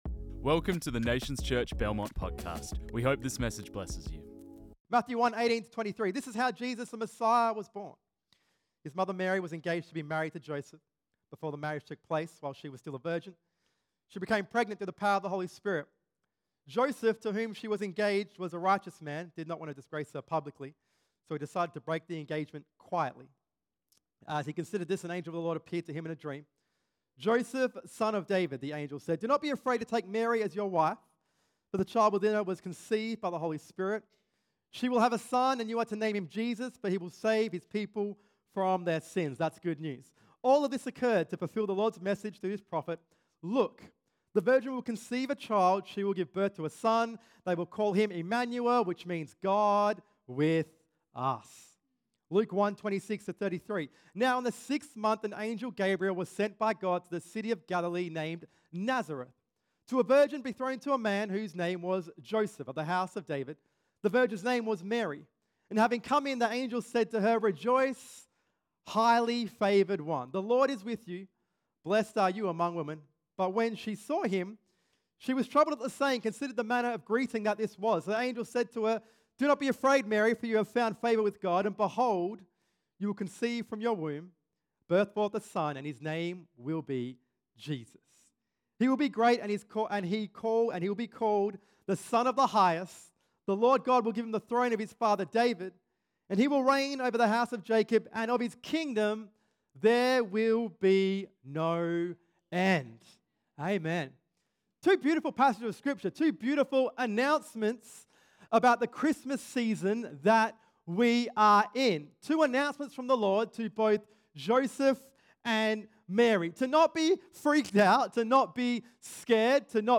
This message was preached on 15 December 2024